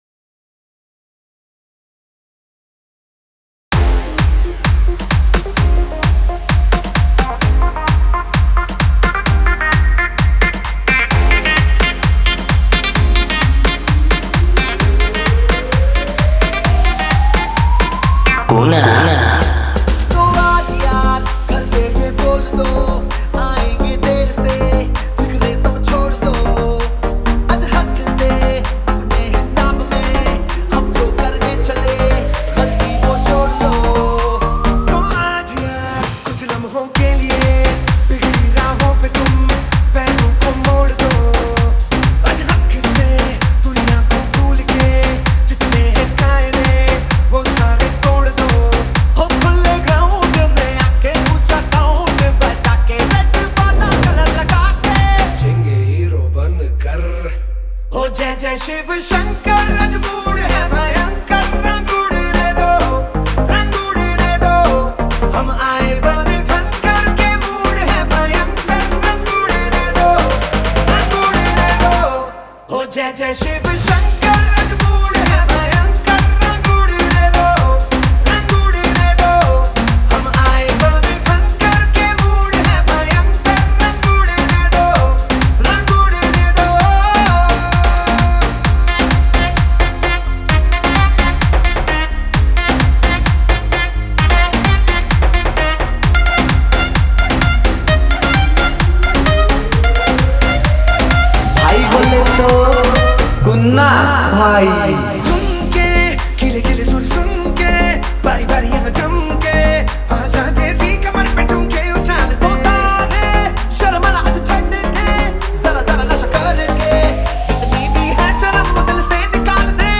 Shivratri Special DJ Remix Songs Songs Download